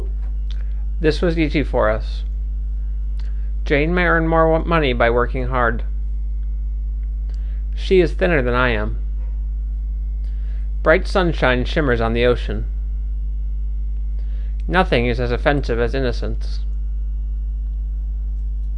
描述：嘈杂的语音0dB
Tag: 语音 噪音 提高